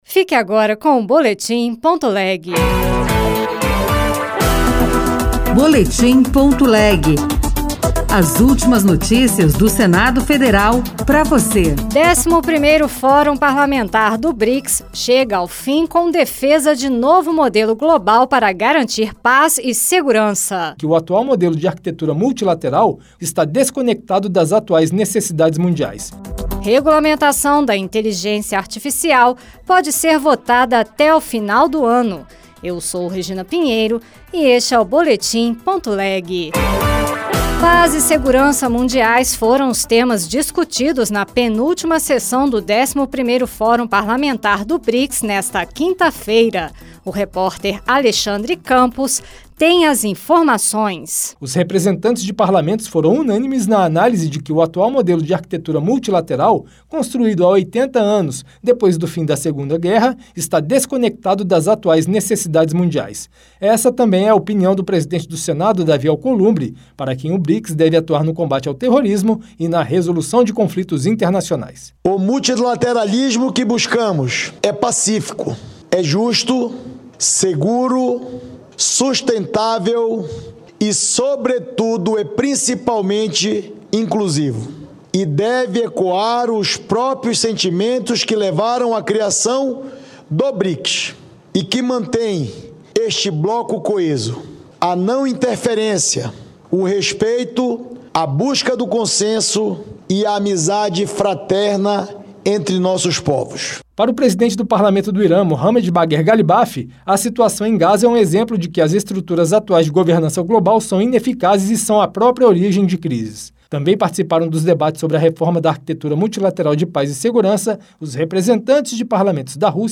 Boletim - Edição das 22h